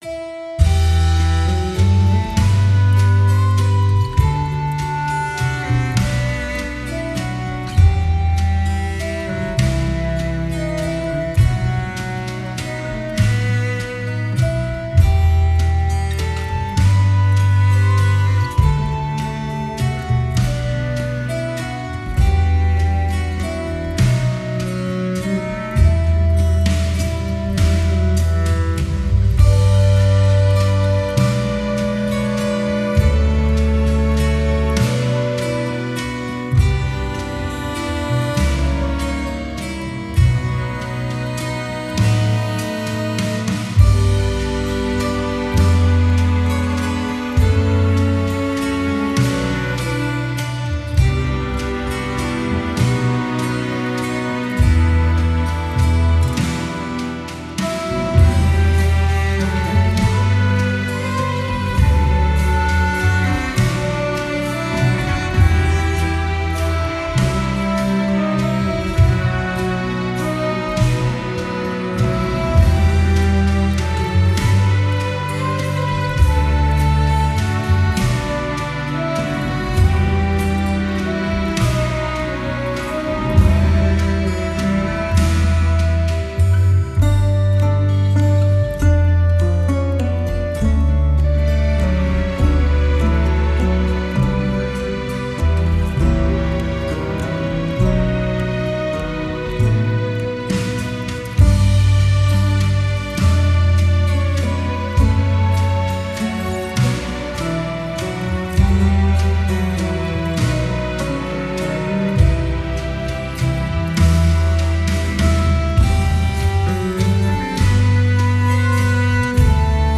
Genre: Pop-Folk.